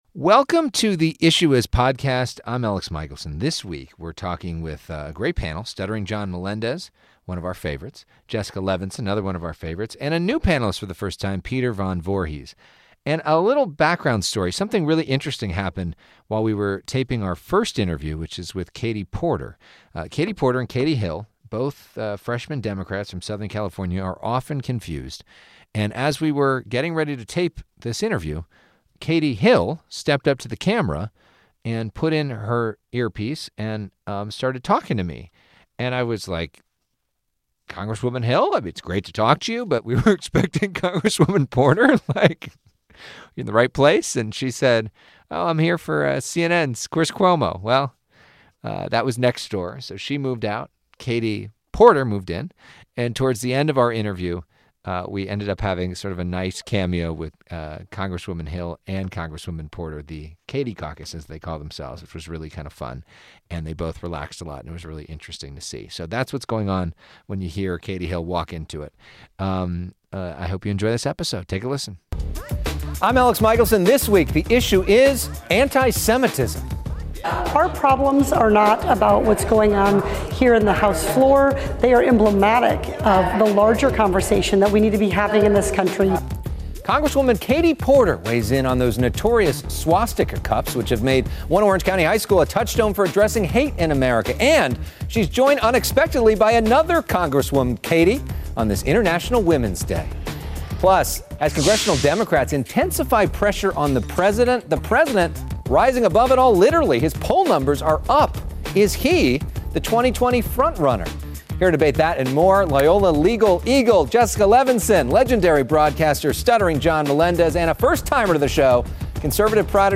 We speak with Rep. Katie Porter, a Democrat from California's 45th district. She talks about the spat of Anti-Semitism, why she's opposed to the Green New Deal, and why she's undecided in the presidential race. Our interview includes a cameo by Rep. Katie Hill, who is often confused for Rep. Katie Porter. Then, our panel on 2020, the census, and Michael Jackson's legacy.